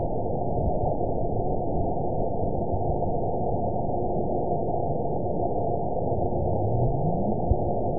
event 911640 date 03/05/22 time 20:46:52 GMT (3 years, 3 months ago) score 9.60 location TSS-AB01 detected by nrw target species NRW annotations +NRW Spectrogram: Frequency (kHz) vs. Time (s) audio not available .wav